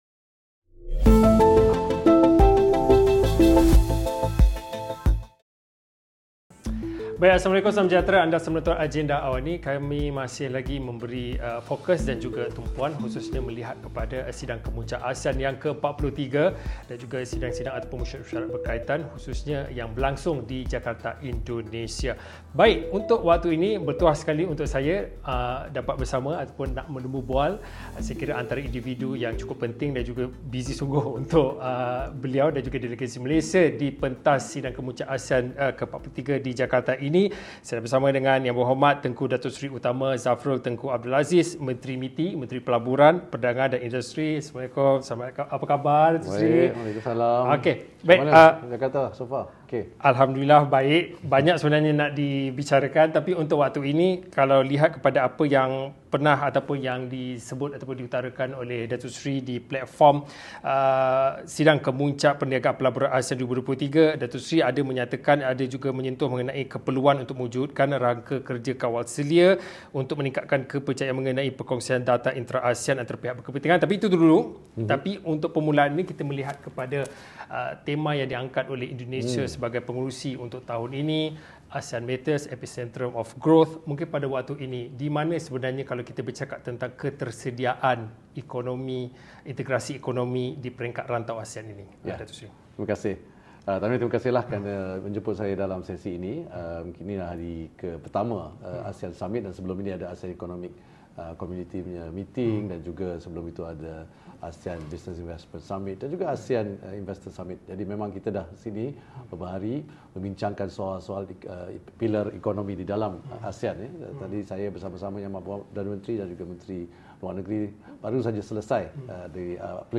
Bagaimana cara untuk melonjakkan integrasi ekonomi ASEAN termasuk perdagangan sesama negara anggota dan rakan luarnya? Temu bual bersama Menteri Pelaburan, Perdagangan dan Industri (MITI), Tengku Datuk Seri Zafrul Abdul Aziz 8.30 malam ini.